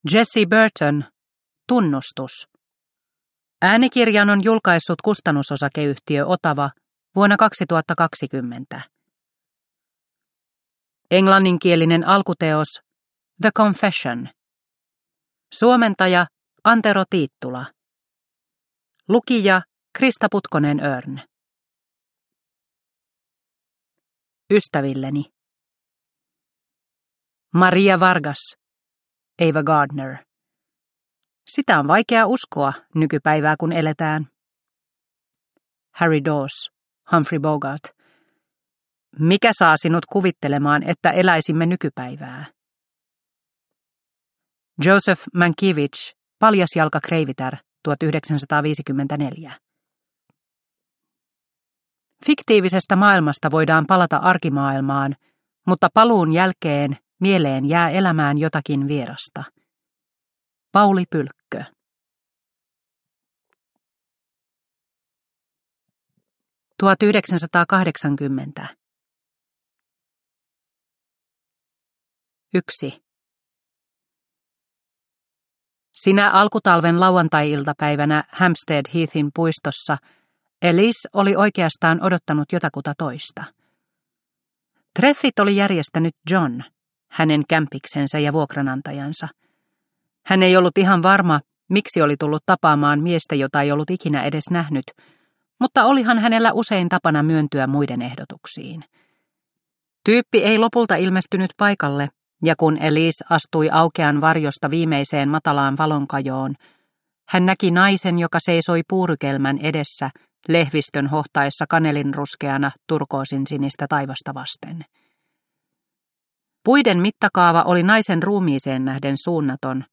Tunnustus – Ljudbok – Laddas ner